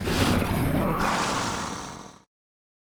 PixelPerfectionCE/assets/minecraft/sounds/mob/guardian/guardian_hit4.ogg at mc116
guardian_hit4.ogg